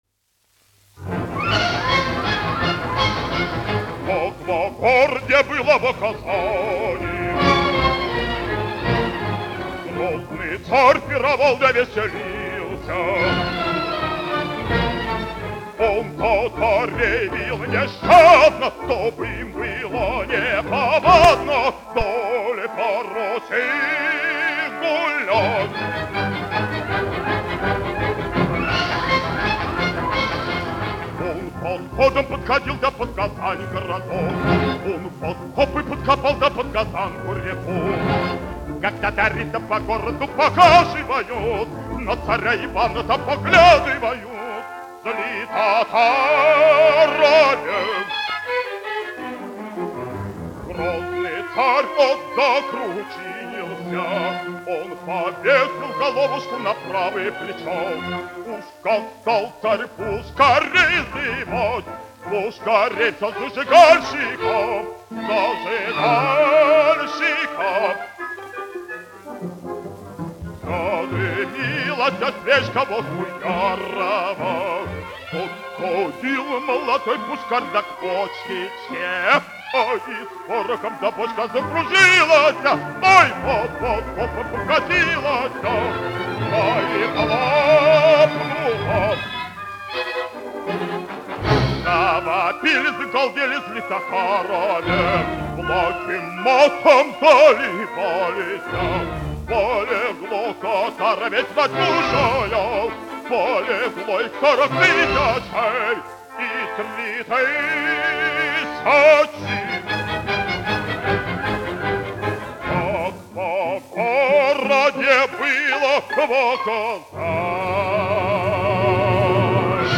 Пирогов, Александр, 1899-1964, dziedātājs
Мелик-Пашаев, Александр, (Александр Шамильевич), 1905-1964, diriģents
Большой театр СССР. Оркестр, izpildītājs
1 skpl. : analogs, 78 apgr/min, mono ; 25 cm
Operas--Fragmenti
Skaņuplate